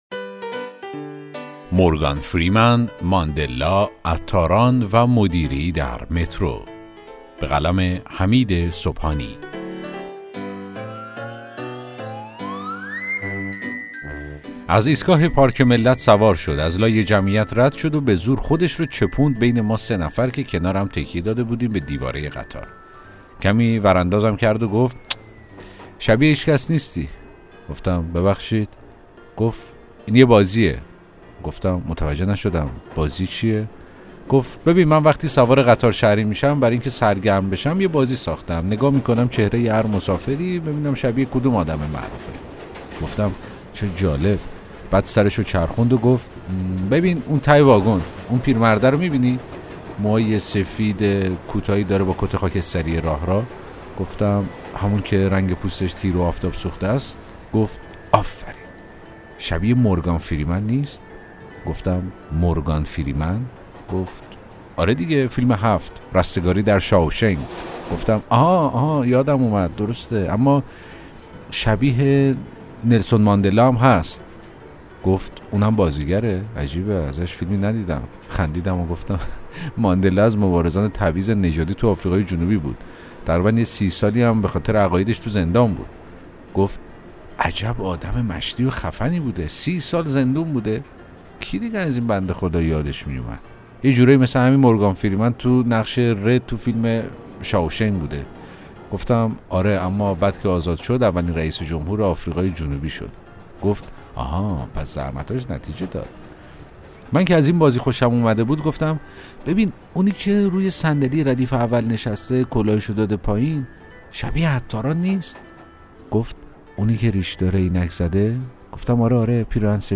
داستان صوتی: مورگان فریمن، ماندلا، عطاران و مدیری در مترو